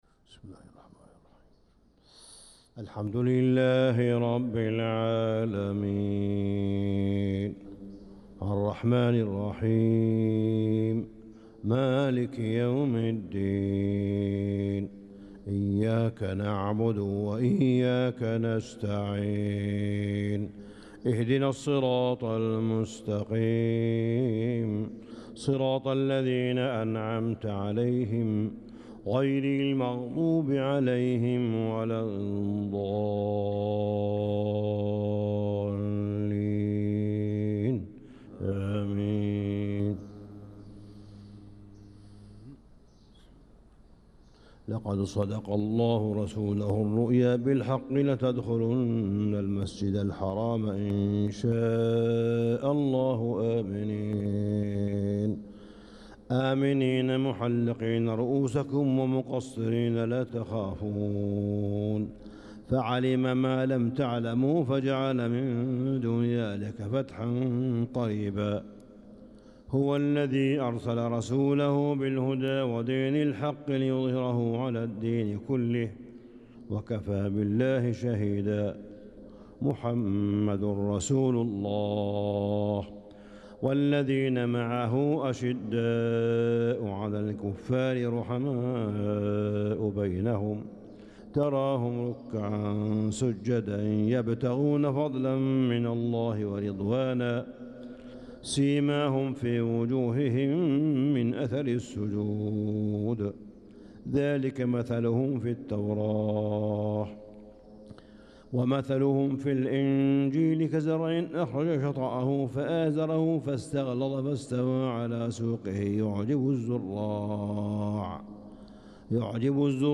صلاة الفجر للقارئ صالح بن حميد 28 ذو القعدة 1445 هـ
تِلَاوَات الْحَرَمَيْن .